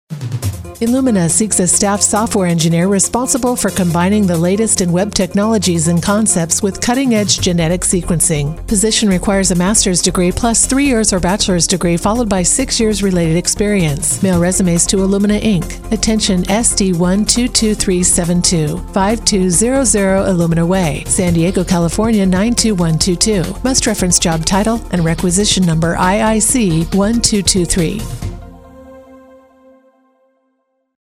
Commercial Corporate
My voiceover style is consistently described as authentic, calm, and trustworthy, offering a smooth, conversational and friendly delivery that truly connects.
Operating from a professional home studio utilizing Adobe Audition, I meticulously record, produce and deliver pristine, polished voiceover recordings with exceptional attention to detail.